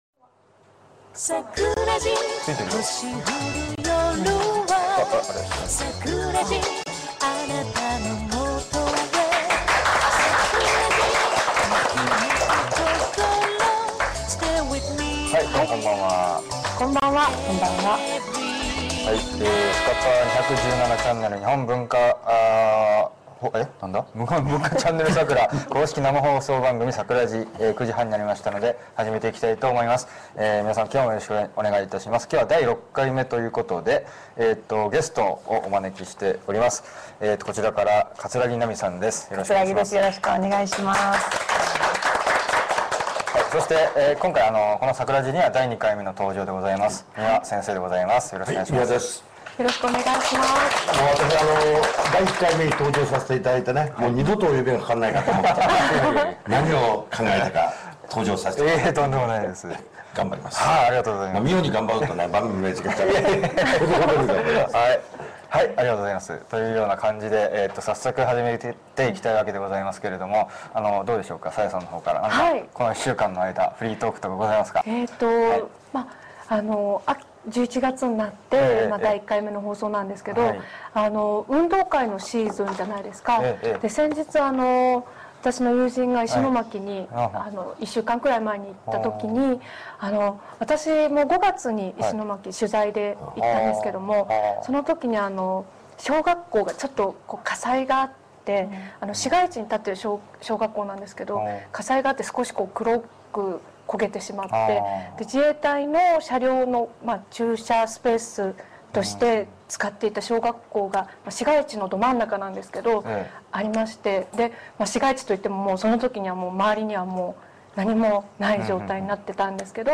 今回の動画ですが、冒頭３分ほど、技術トラブルにより画質・音質共に非常に見づらくなっております。